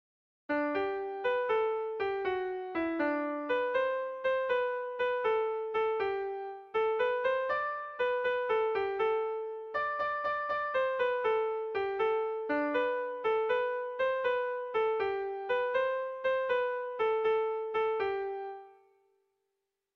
Melodías de bertsos - Ver ficha   Más información sobre esta sección
Arnegi < Garazi < Baxenabarre < Euskal Herria
ABDEB